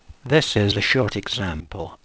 During synthesis each word desired to have emphasis is constructed from the only those segment with that emphasis feature. We can synthesize emphasized examples like this